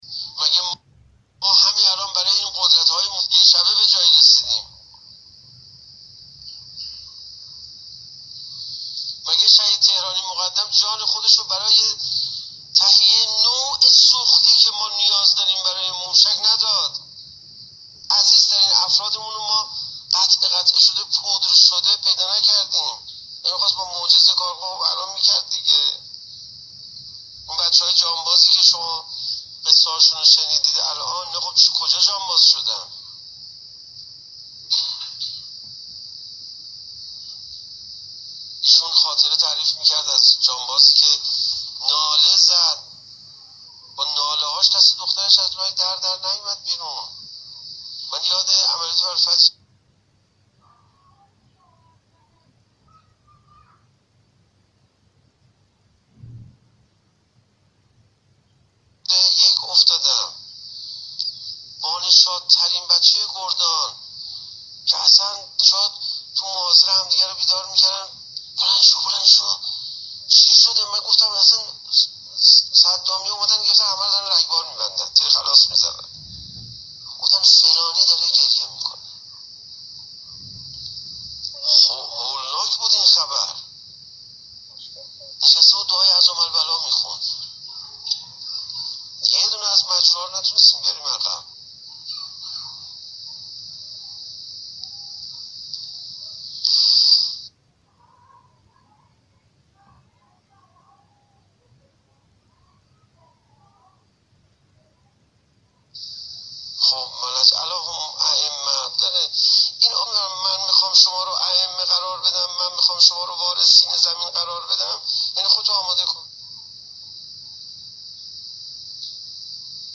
شب گذشته در لانه جاسوسی به ایراد سخنرانی پرداخت